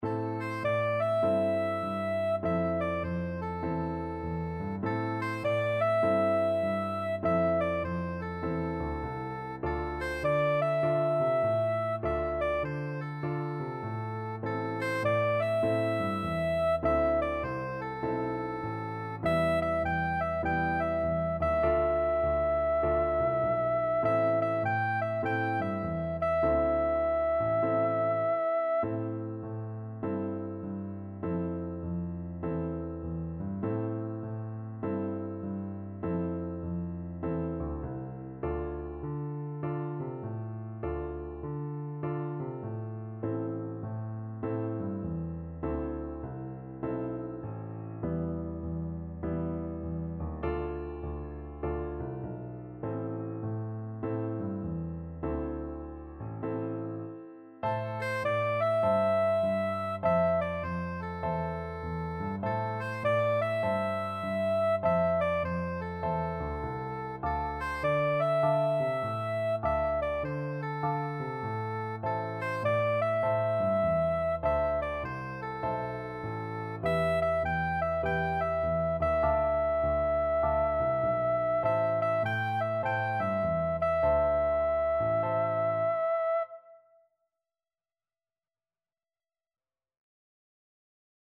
A simple blues tune with an improvisatory section
4/4 (View more 4/4 Music)
With a swing!